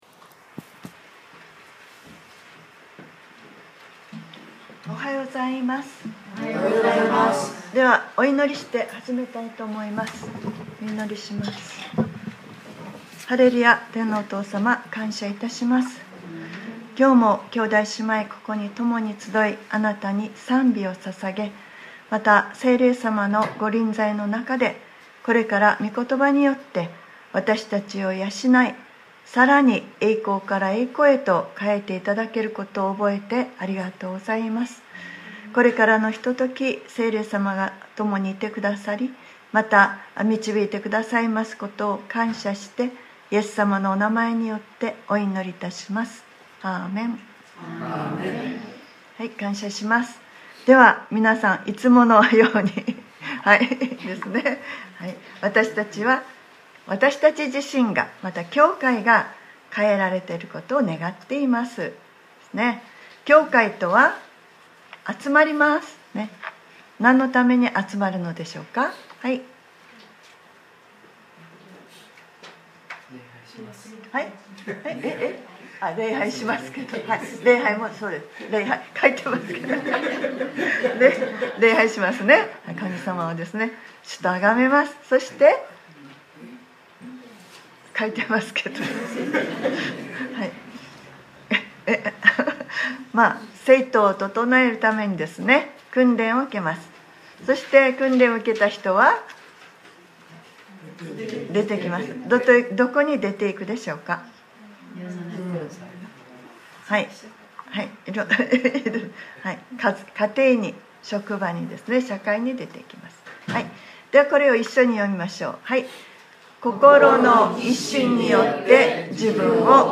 2020年1月19日（日）礼拝説教『参加型：弟子の姿』